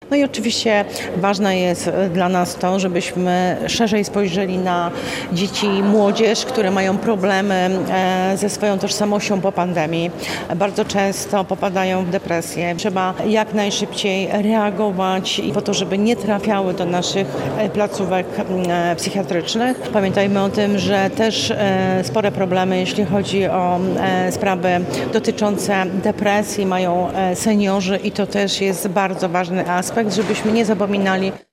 Członek zarządu woj. mazowieckiego, Janina Ewa Orzełowska podkreśla, że ważny jest rozwój infrastruktury, ale także dalsze wspieranie służby zdrowia: